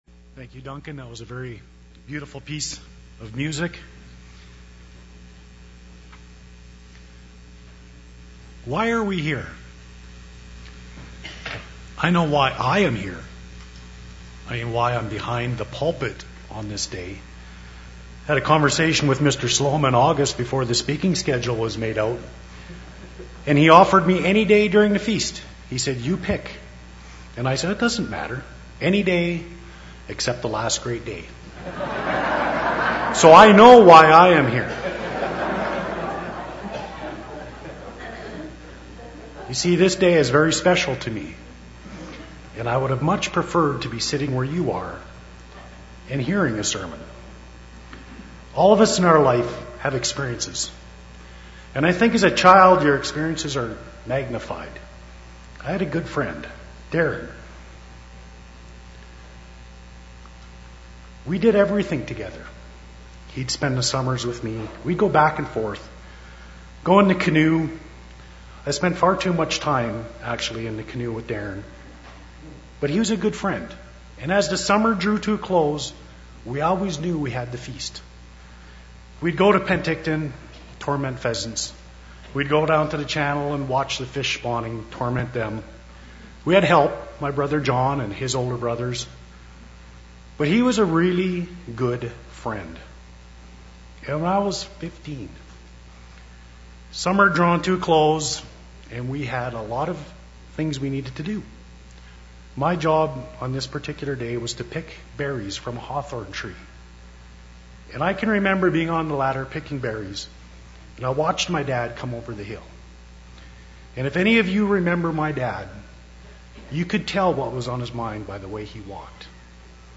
This sermon was given at the Canmore, Alberta 2011 Feast site.